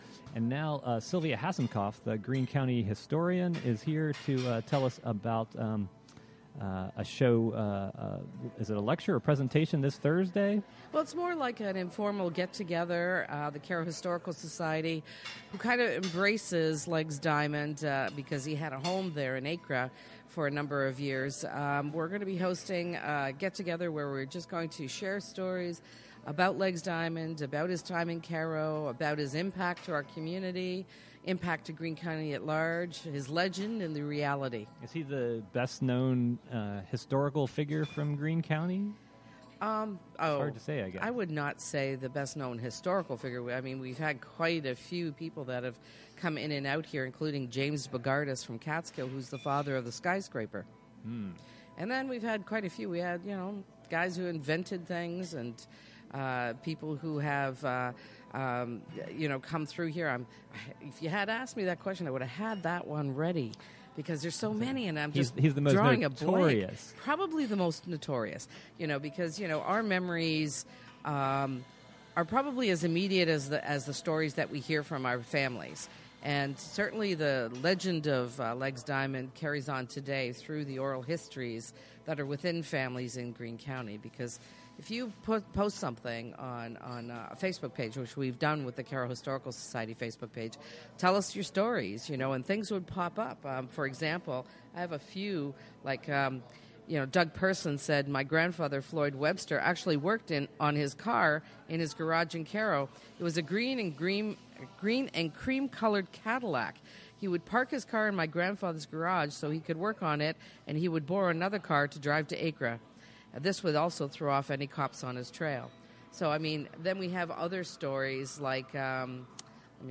WGXC Morning Show Contributions from many WGXC programmers. broadcasts Live from HiLo : Oct 16, 2018: 9am - 11am Join us for coffee in Catskill!